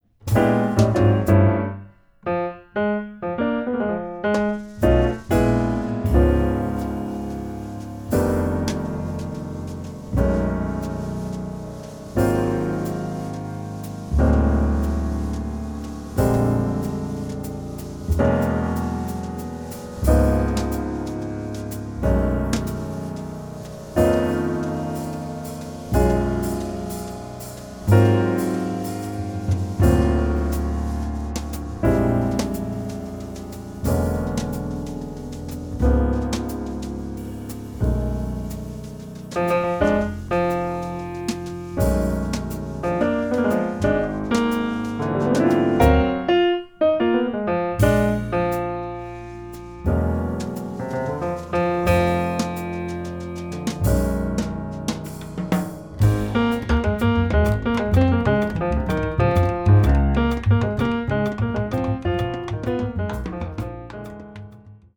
とても息の合ったアンサンブルを聴かせているのだ。
1日目のピアノトリオの録音が終わったところに、